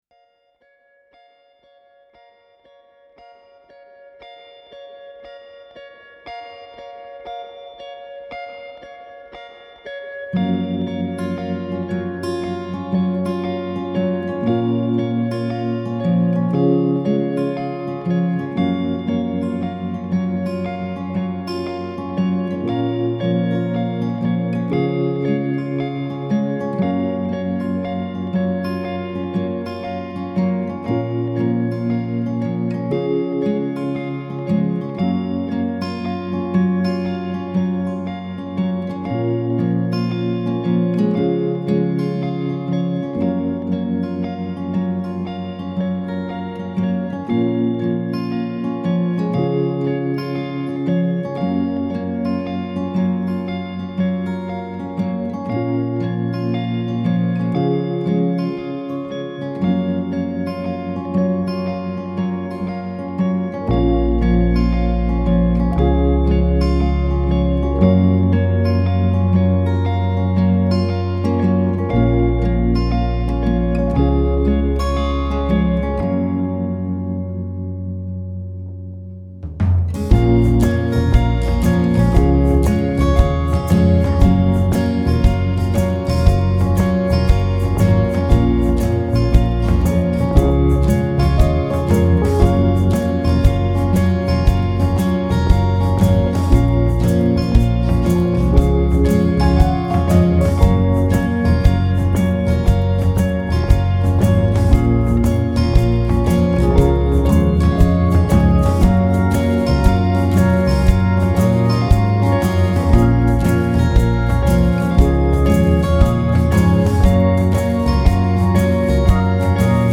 a beautifully textured & mesmeric song